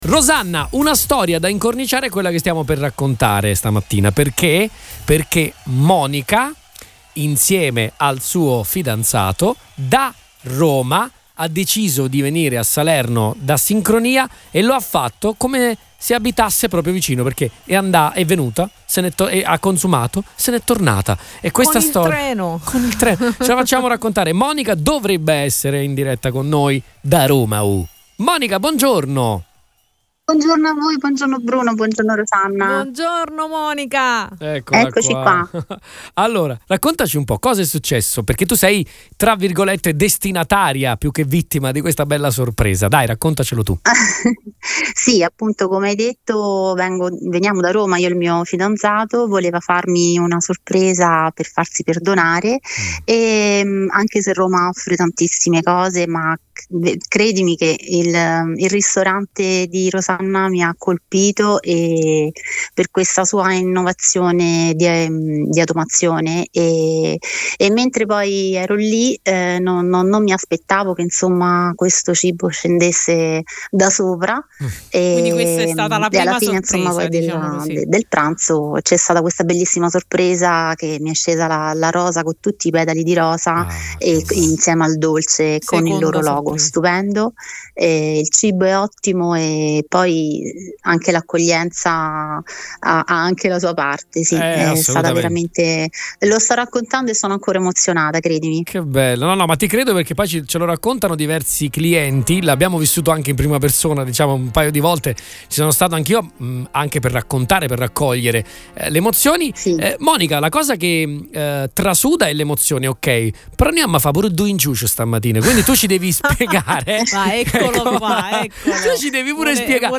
e che oggi raccontano in diretta su Radio Punto Nuovo.